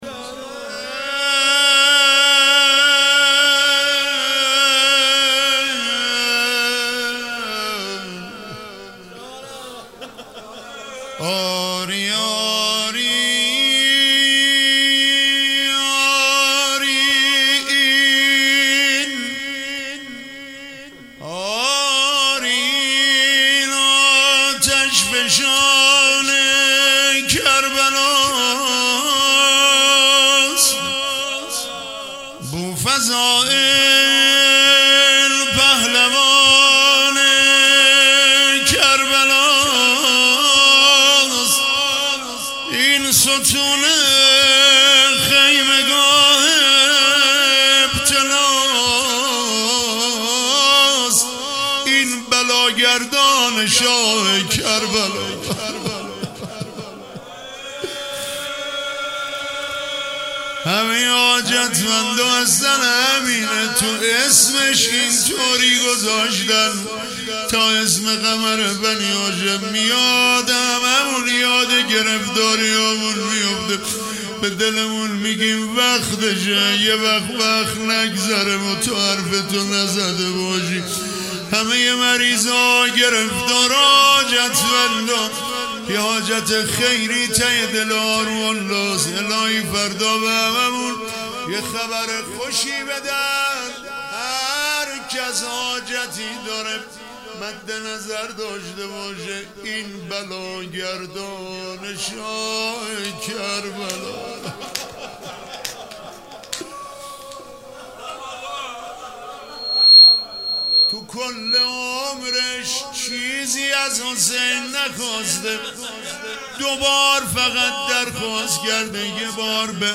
روضه هیئت رایه الرضا علیه السلام